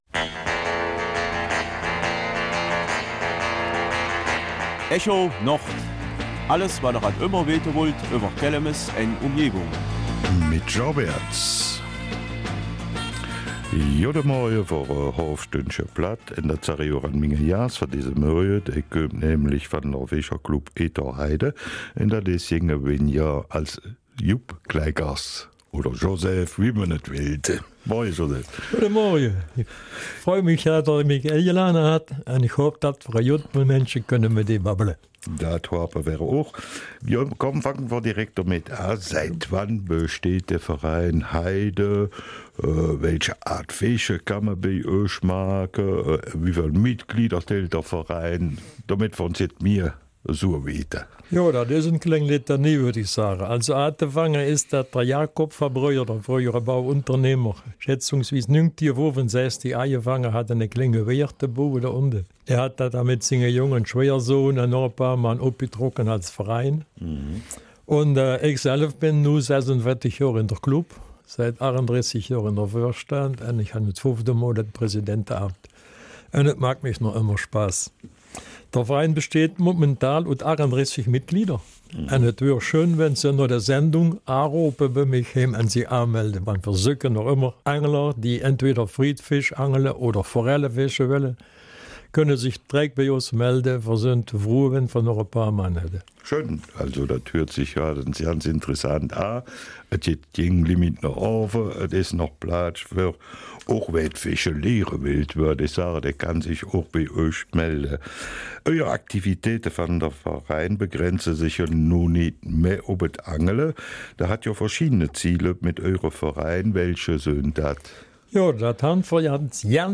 Kelmiser Mundart: Etang Heide Kelmis 20.